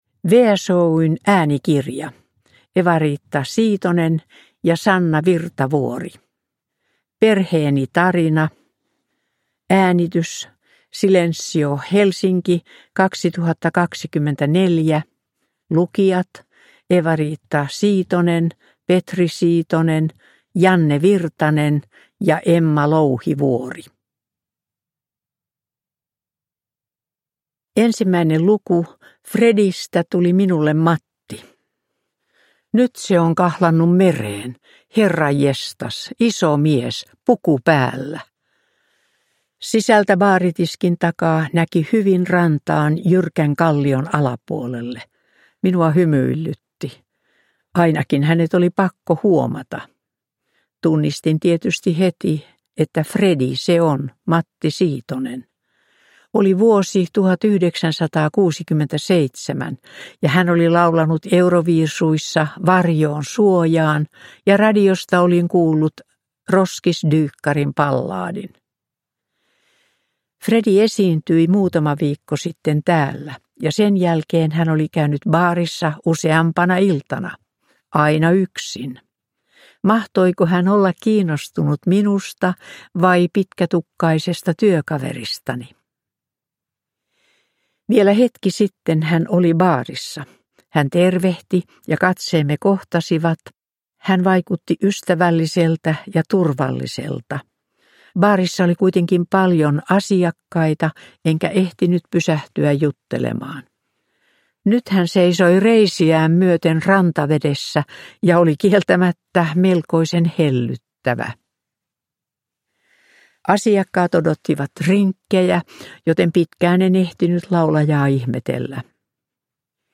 Perheeni tarina – Ljudbok